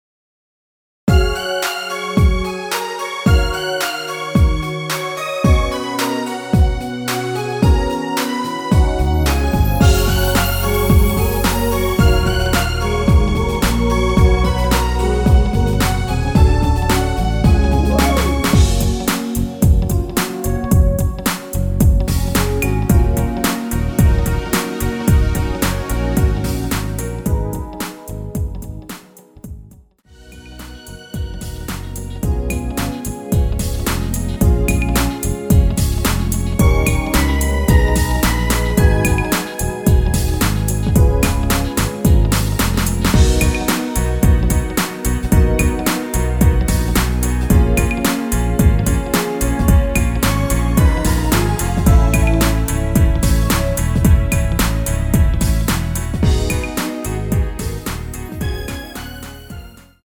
원키에서(-1)내린 (1절+후렴) MR입니다.
Gb
앞부분30초, 뒷부분30초씩 편집해서 올려 드리고 있습니다.
중간에 음이 끈어지고 다시 나오는 이유는